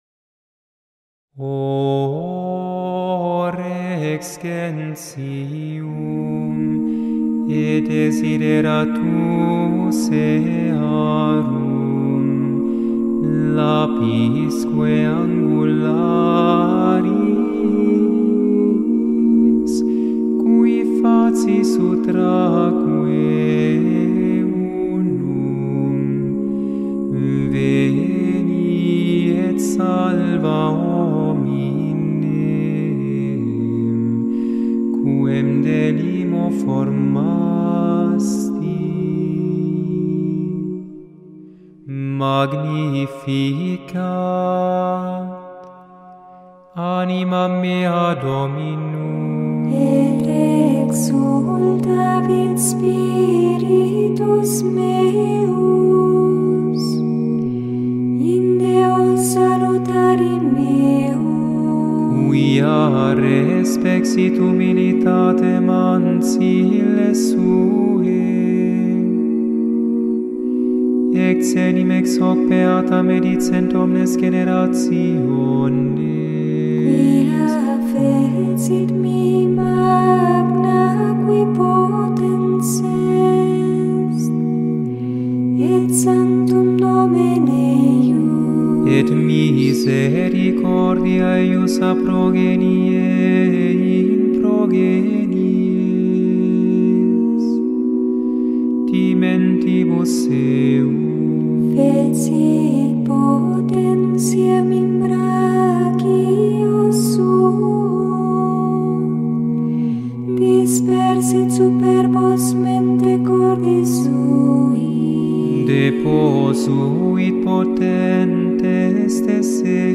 • Chaque jour, du 17 au 23 décembre (soir), on chante une antienne « Ô » dédiée à un Nom divin du Christ, tirée des prophéties de l’Ancien Testament.
Et voici des manifique enregistrement de Harpa Dei sur YouTube :
22dec-O-REX-GENTIUM-O-Antiphon-and-Magnificat.mp3